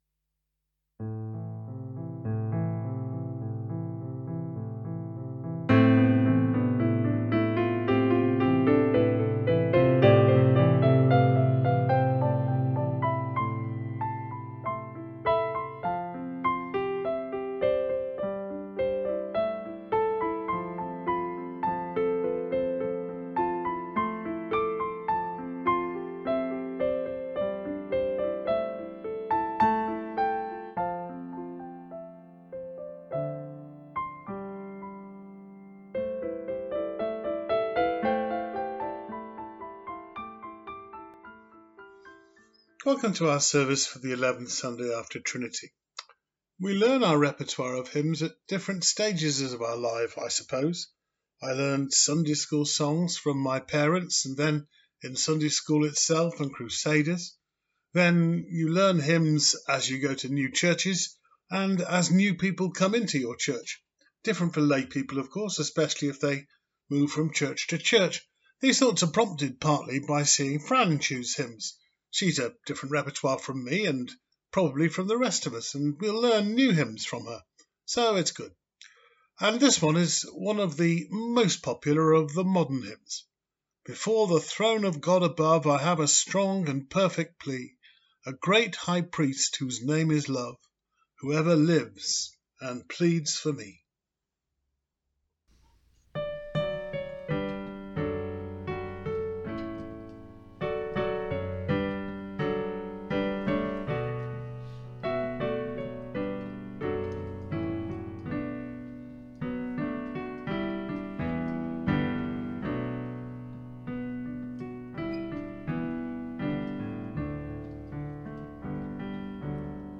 Time Sun, 11th August, 2024, 06:00 - 07:00 Service Type Morning Worship Speaking the truth in love.